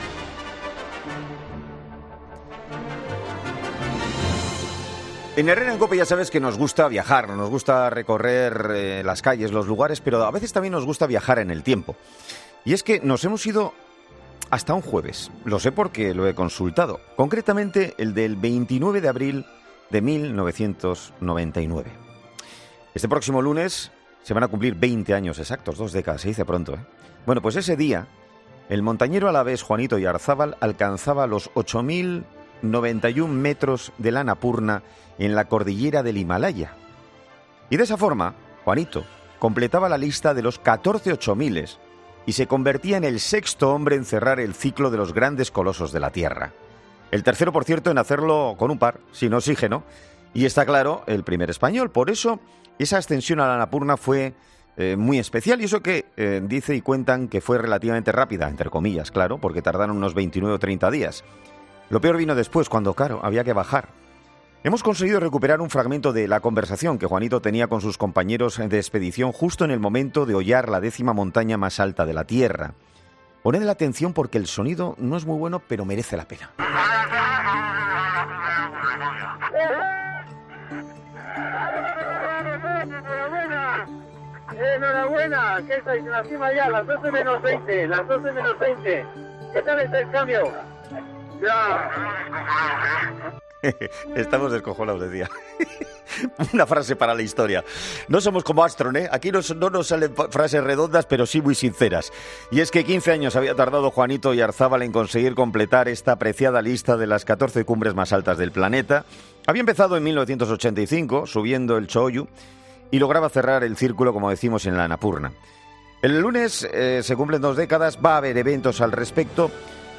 Para recordar este acontecimiento, este viernes ha sido entrevistado en 'Herrera en COPE', donde ha dicho que “las condiciones del tiempo fueron muy favorables”, por lo que en vez de esperar más tiempo, decidieron alcanzar la cumbre en solo 28 días .